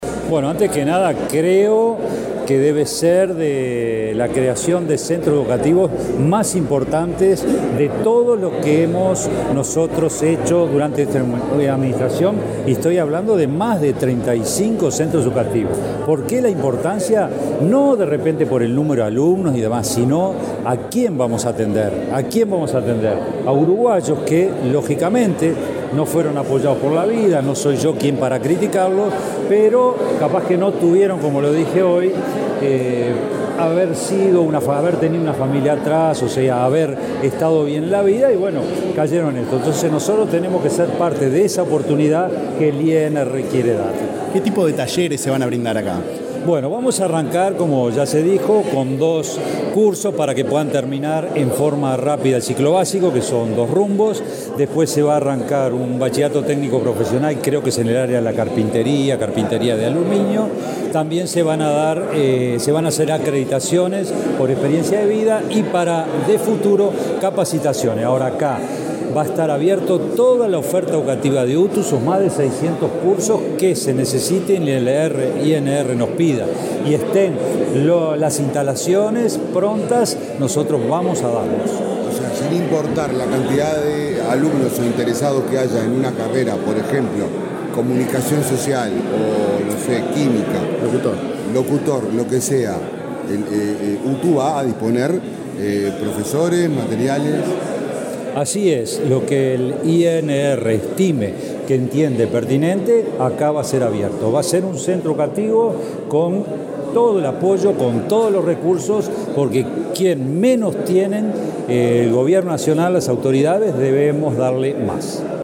Declaraciones del director general de UTU, Juan Pereyra
El director del Instituto Nacional de Rehabilitación (INR), Luis Mendoza; el director general de la Universidad del Trabajo del Uruguay (UTU), Juan Pereyra: la presidenta de la Administración Nacional de Educación Pública (ANEP), Virginia Cáceres, y el ministro del Interior, Nicolás Martinelli, participaron, este martes 12 en la unidad n.° 4, Santiago Vázquez, en el acto de firma de un convenio que permitirá instalar la primera UTU en el sistema penitenciario. Luego Pereyra dialogó con la prensa.